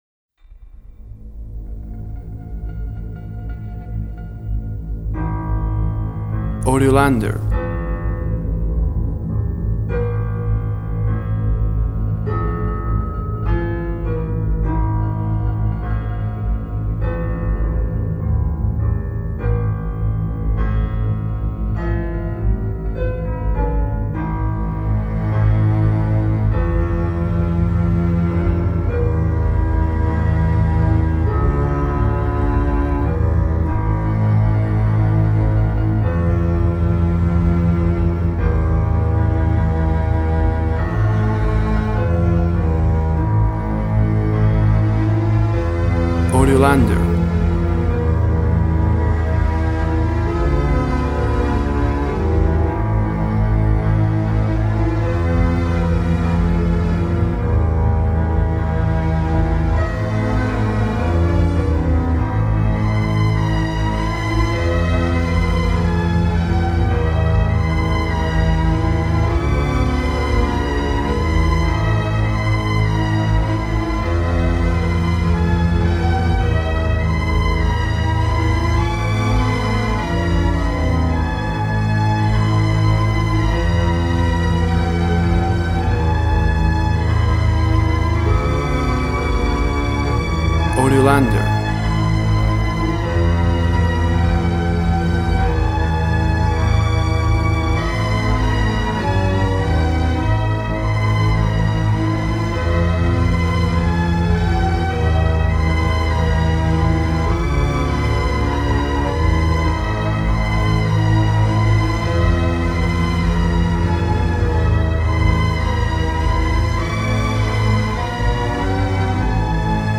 Dramatic underscore, evokes suspense and melancholy.
Tempo (BPM) 65